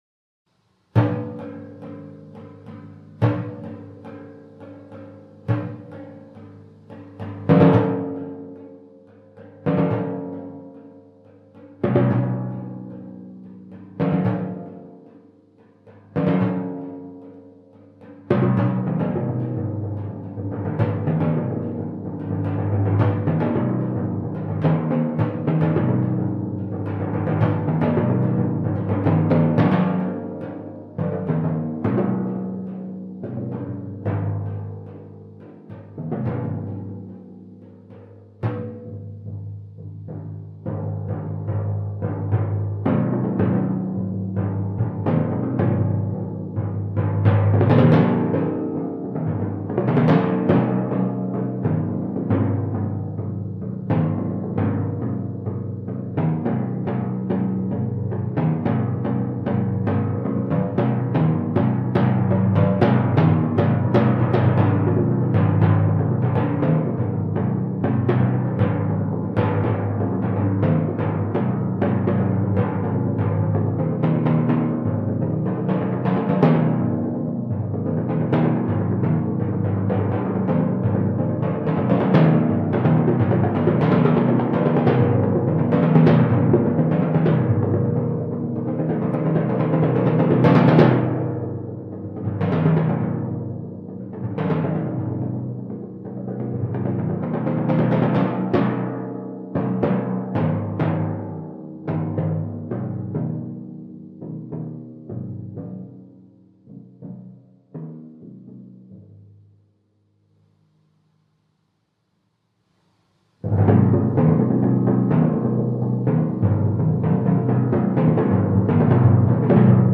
Genre: Solo Timpani
4 Timpani